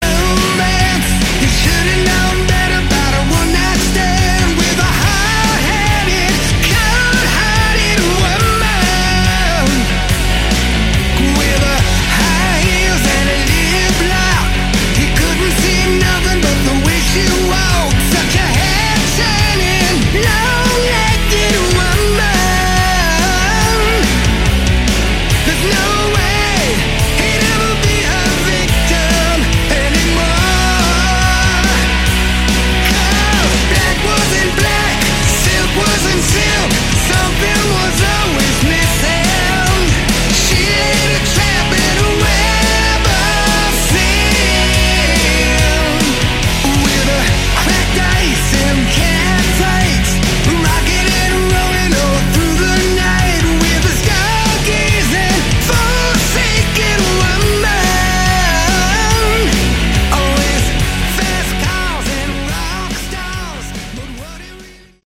Category: Melodic Rock
lead & backing vocals